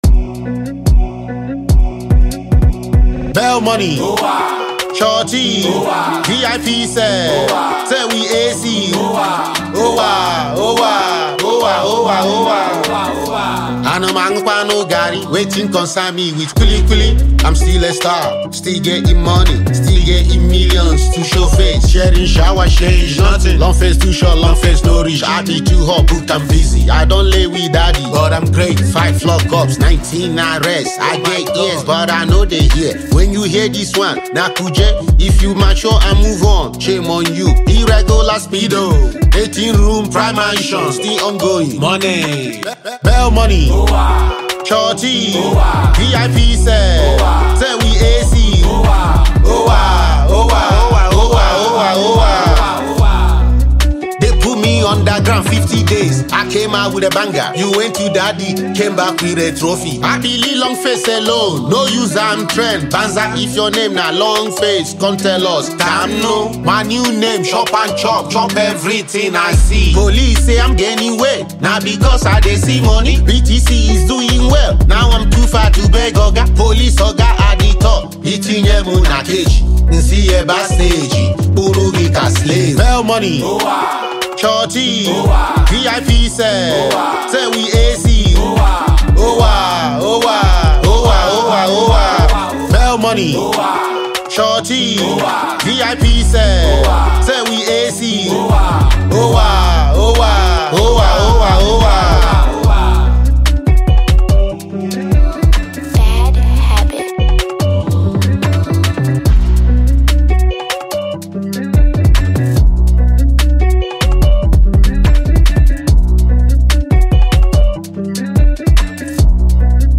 Nigerian talented rapper and singer